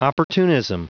Prononciation du mot opportunism en anglais (fichier audio)
Prononciation du mot : opportunism